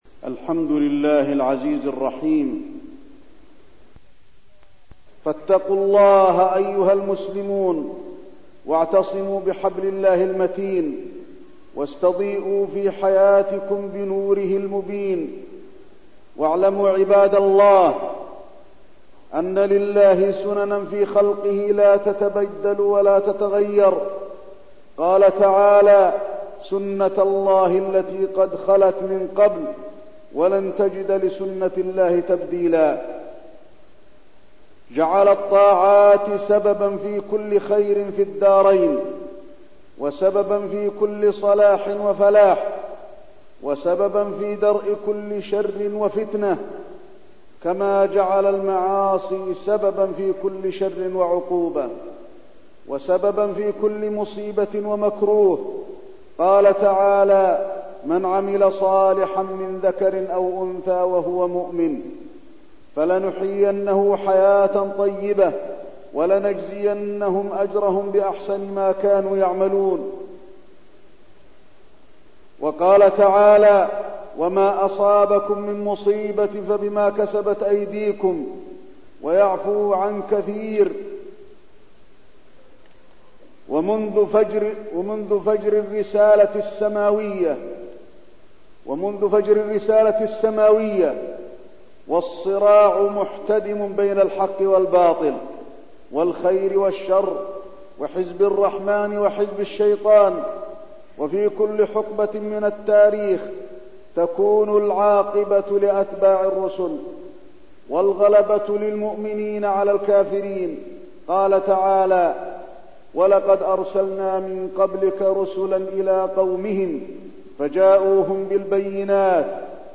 تاريخ النشر ١٢ جمادى الأولى ١٤١٣ هـ المكان: المسجد النبوي الشيخ: فضيلة الشيخ د. علي بن عبدالرحمن الحذيفي فضيلة الشيخ د. علي بن عبدالرحمن الحذيفي دفع الظلم عن المسلمين